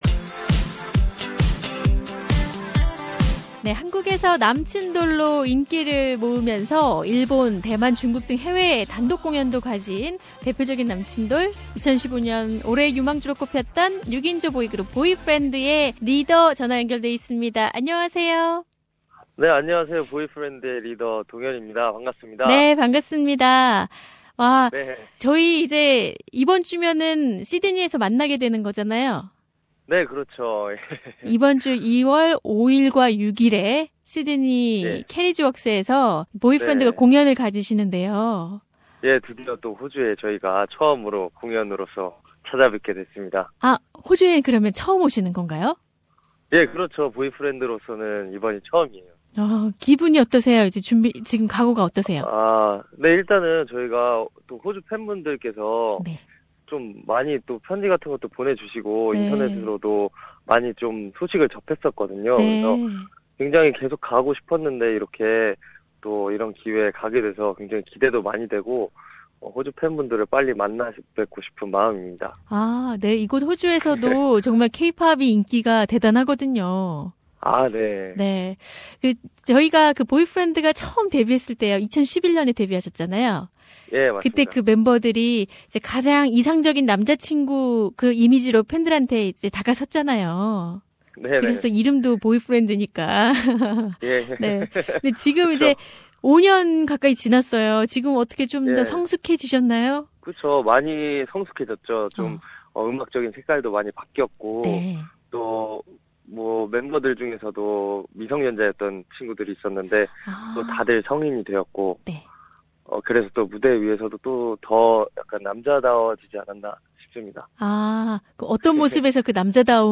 K-Pop boy band Boyfriend's leader DongHyun did not hide his excitement of the upcoming concerts in Sydney to mark the Lunar New Year in a special interview with SBS Radio.